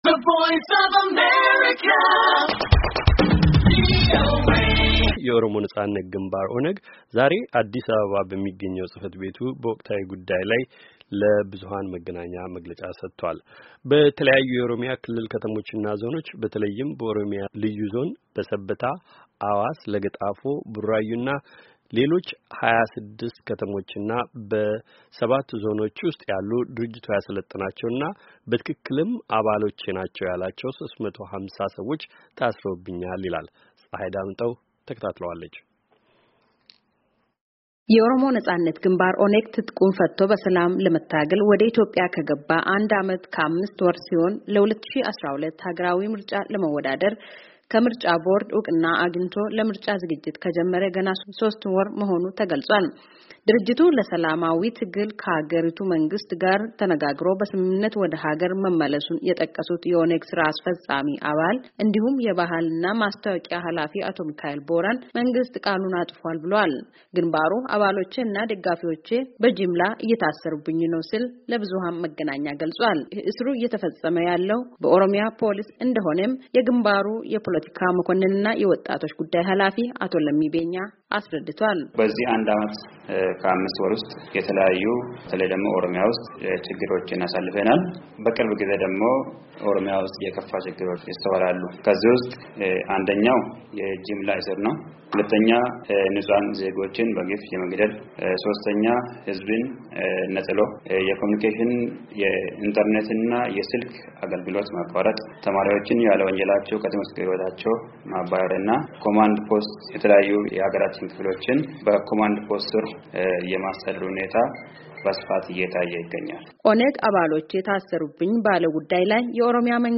የኦሮሞ ነፃነት ግንባር /ኦነግ/ ዛሬ አዲስ አበባ በሚገኘው ፅህፈት ቤቱ በወቅታዊ ጉዳይ ላይ ለብዙሃን መገናኛ መግለጫ ሰጥቷል።